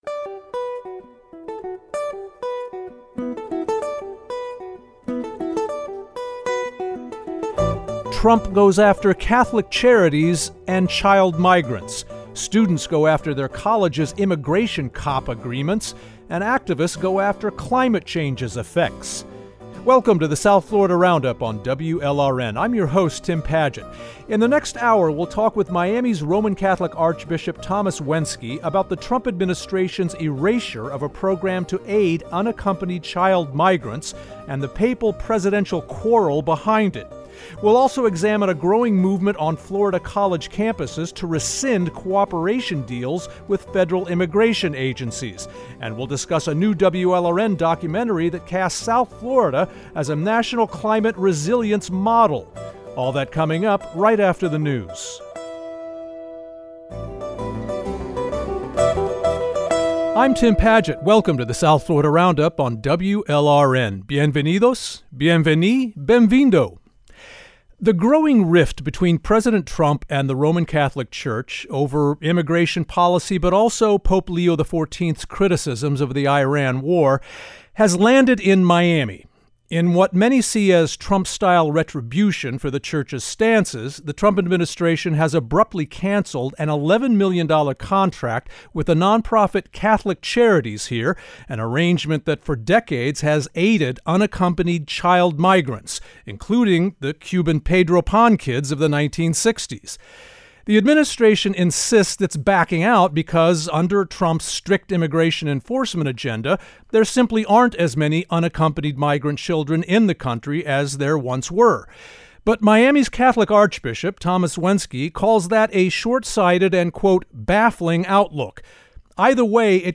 Each week, journalists and newsmakers from South Florida analyze and debate some of the most topical issues from across the region.